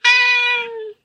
猫の甘え声２
cat_sweet_voice2.mp3